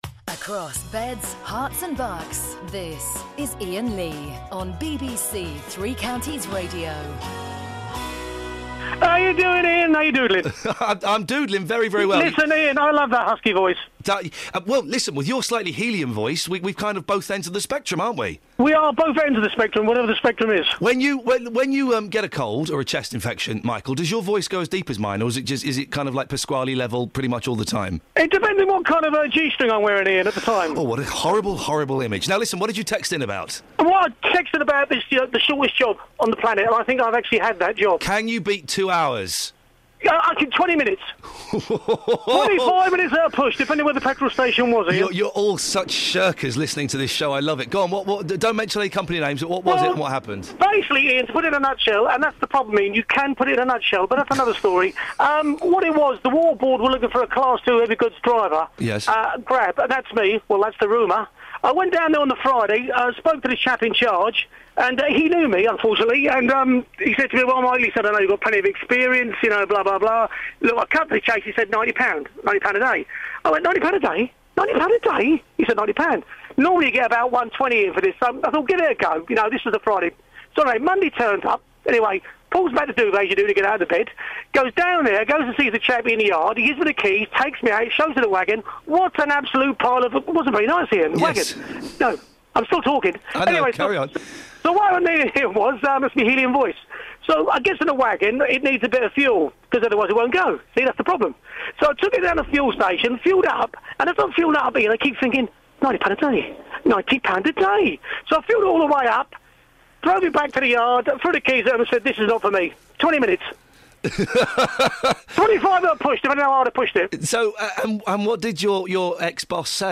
What's the longest you've ever stayed in the same job for? And do we have any loyalty to our employers anymore? On BBC Three Counties Radio.